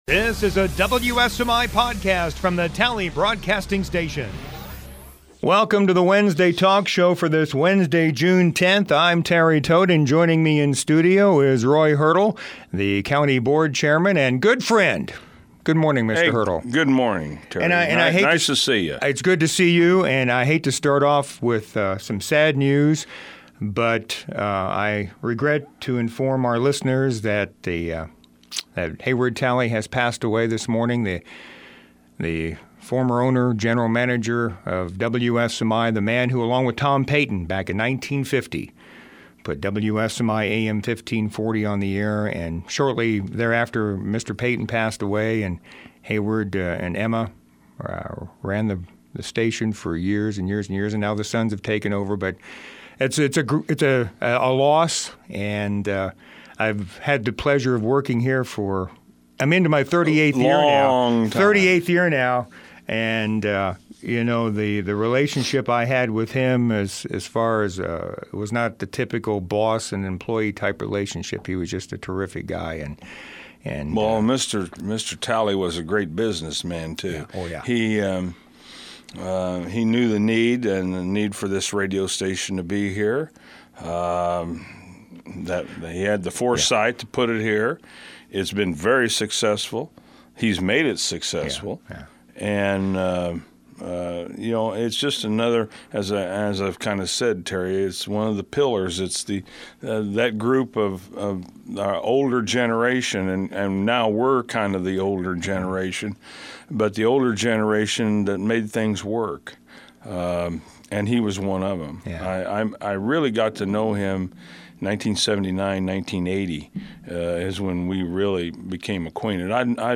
06/10/2015 Wednesday Talk Show Guest: Roy Hertel-Montgomery County Board Chairman and Guest Speaker at this years Montgomery County Cancer Association Birthday Party